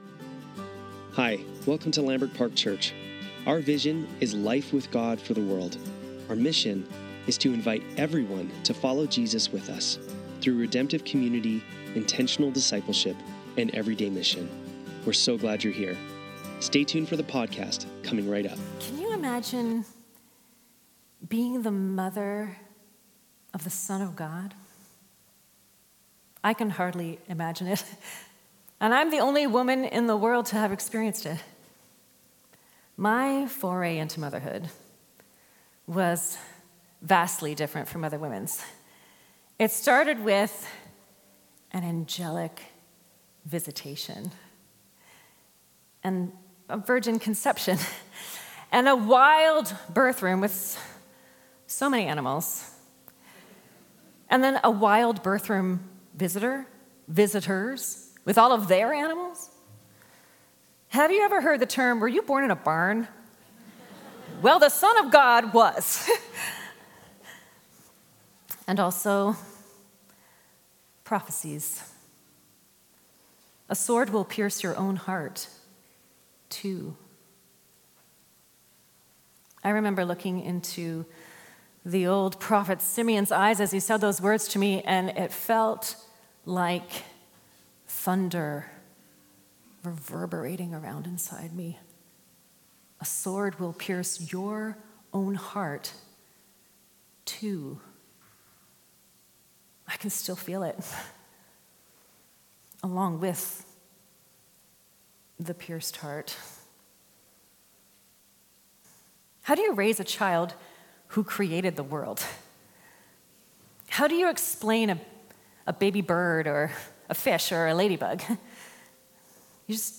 Sunday Service - April 20, 2025